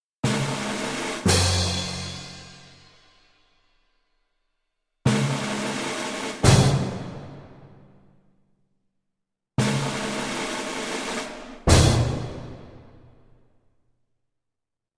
Звук циркового барабана перед рискованным прыжком